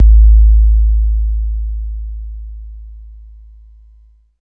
23 808 KICK.wav